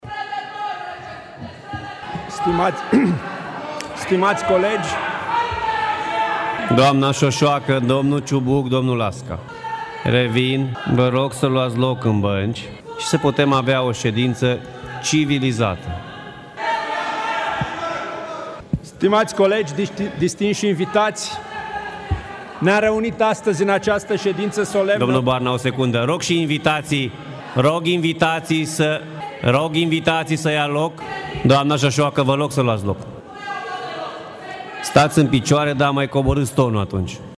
Scandalul făcut de parlamentarii extremiști a continuat, însă, și mai intens… și în momentul în care reprezentantul USR, Dan Barna, a încercat să susțină un discurs
14mai-11-Scandal-la-Barna-stati-in-picioare-dar-mai-coborati-tonul.mp3